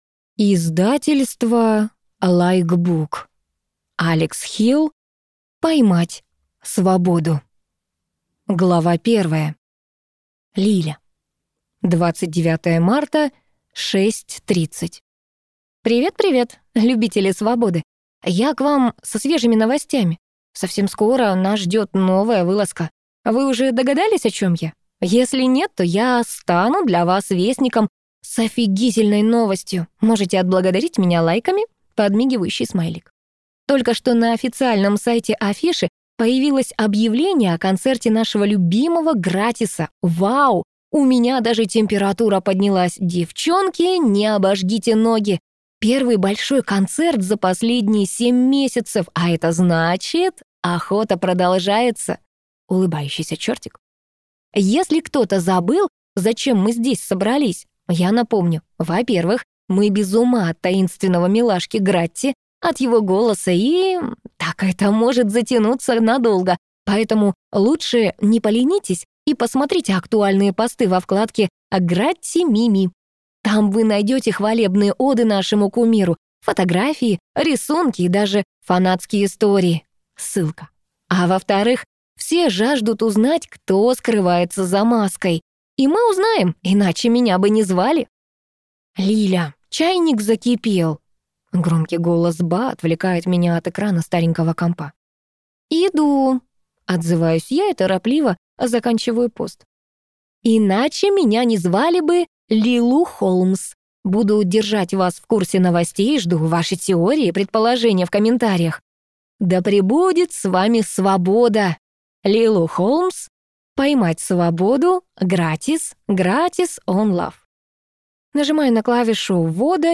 Аудиокнига Поймать свободу | Библиотека аудиокниг